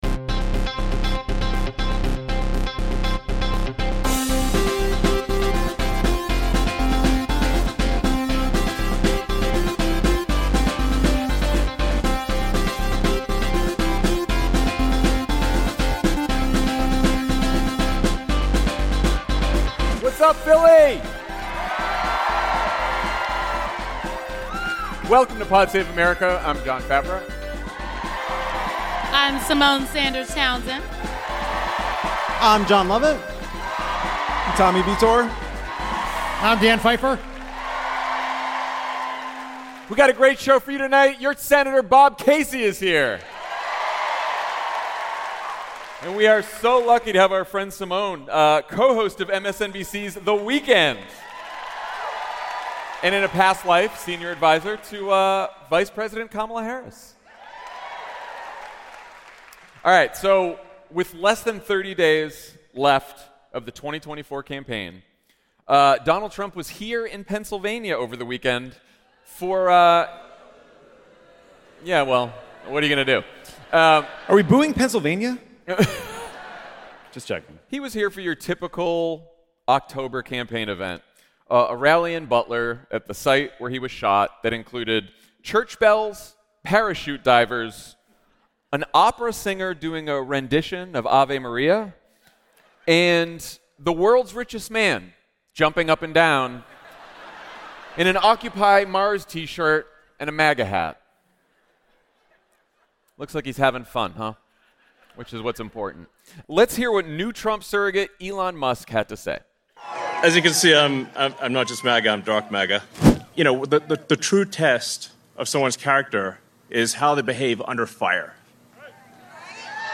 Live from Philly, Jon, Lovett, Tommy, Dan, and MSNBC's Symone Sanders Townsend discuss Trump's rally with Elon Musk, Kamala Harris's media blitz, and reports that she plans to distance herself more from Joe Biden. Then, Senator Bob Casey drops by to nerd out about Pennsylvania electoral maps and to talk about his re-election fight against a Connecticut hedge fund guy—and why Pennsylvania voters have everything on the line this November.